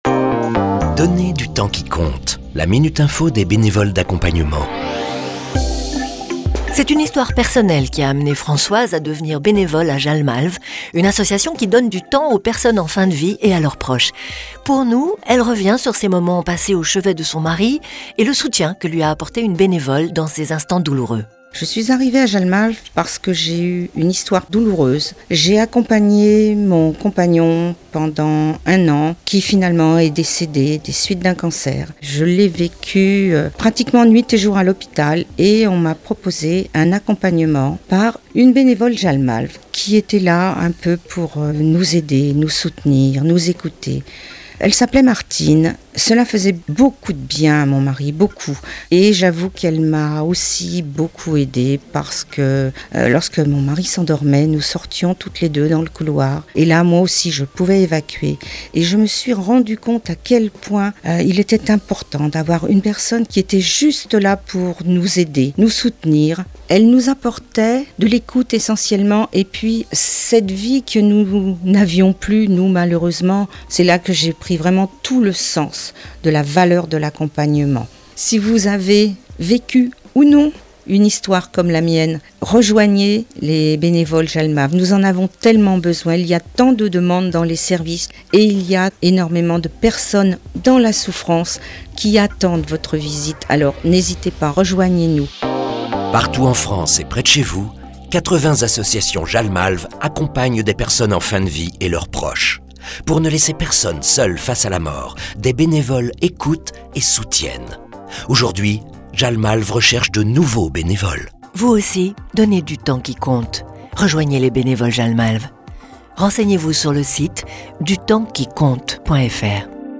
Témoignage d’un proche devenu « bénévole »